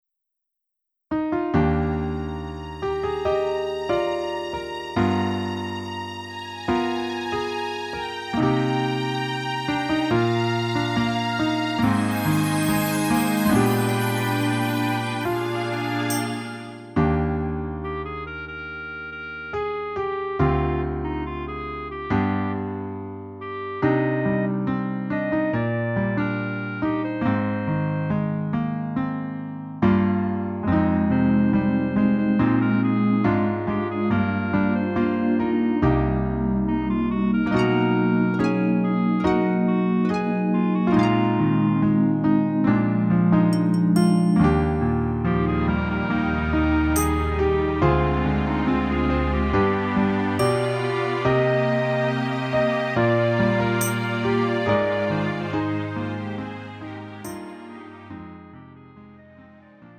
음정 -1키 5:58
장르 가요 구분 Lite MR
Lite MR은 저렴한 가격에 간단한 연습이나 취미용으로 활용할 수 있는 가벼운 반주입니다.